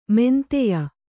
♪ menteya